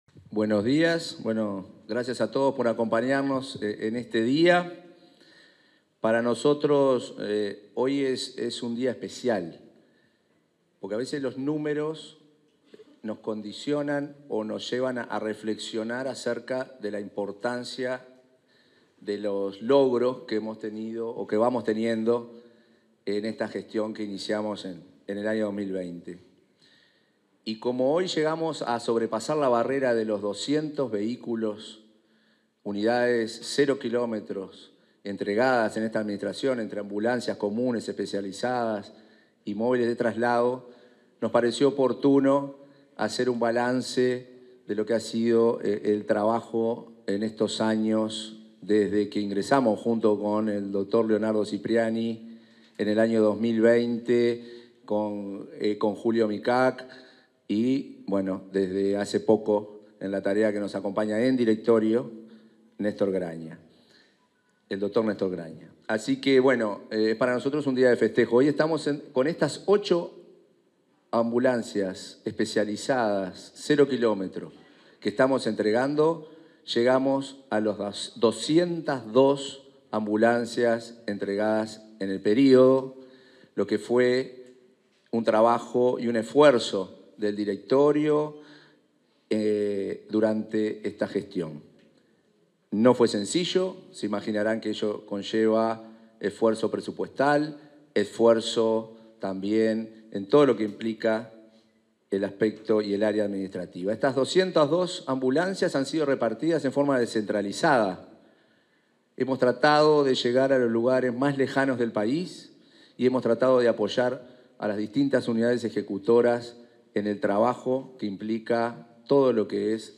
Declaraciones del presidente de ASSE, Marcelo Sosa